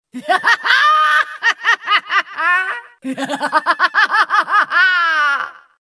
Risada Samira (LoL)
Risada da atiradora Samira, a Rosa do Deserto, de League Of Legends (LoL).
risada-samira-lol.mp3